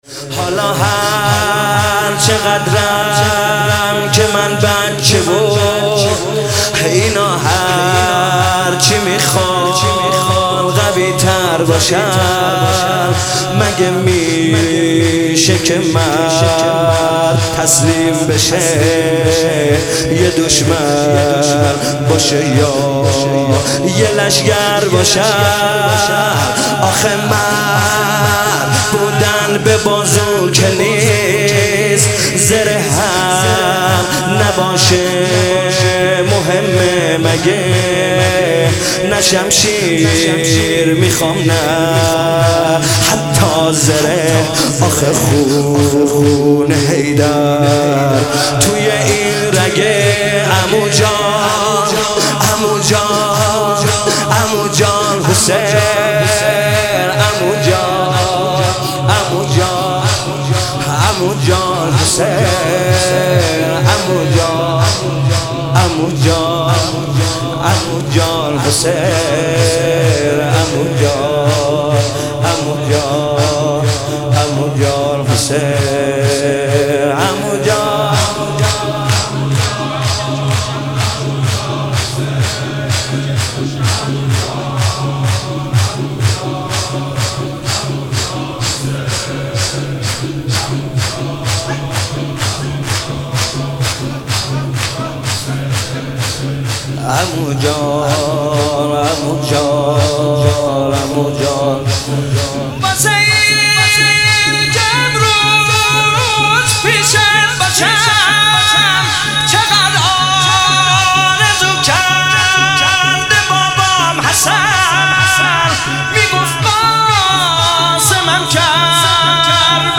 صوتی مراسم ماه مبارک رمضان ۱۳۹۷-شب بیست وششم
منبع هیئت ثارالله (مسجد امام الهادی)